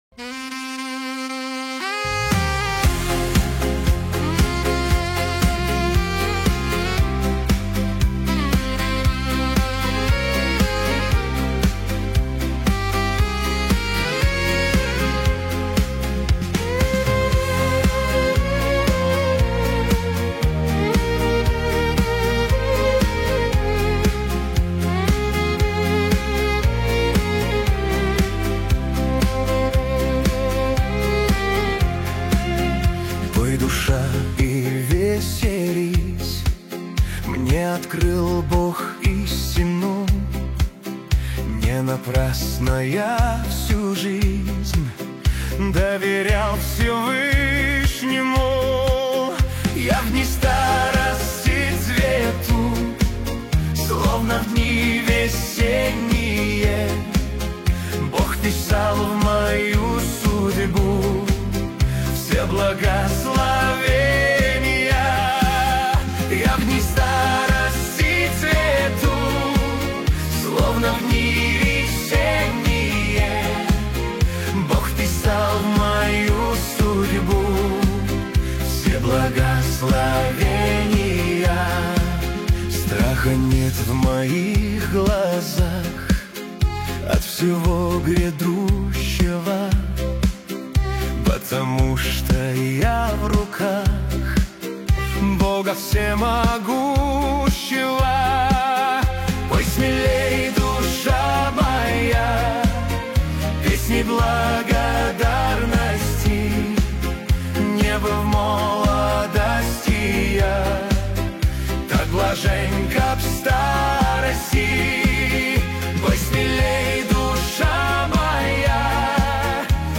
песня ai
7 просмотров 31 прослушиваний 2 скачивания BPM: 116